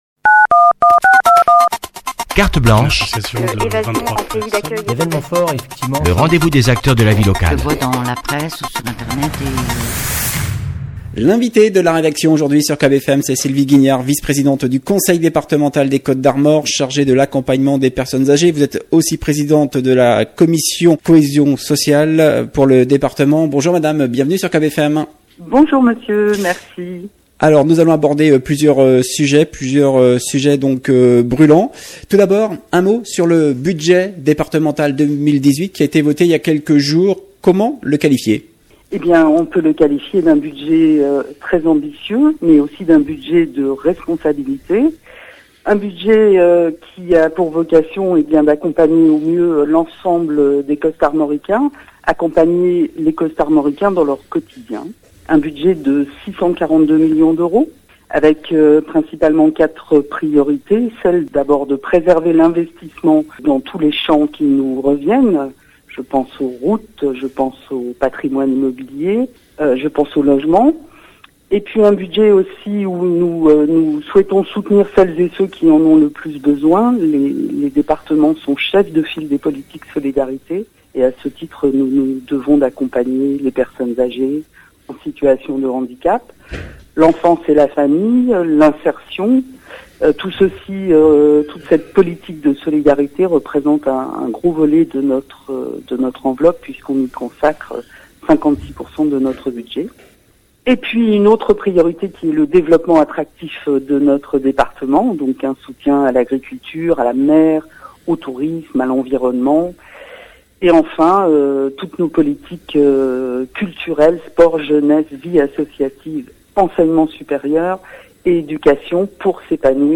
Entretien avec Sylvie Guignard, vice-Présidente du Conseil départemental, chargée de l’accompagnement des personnes âgées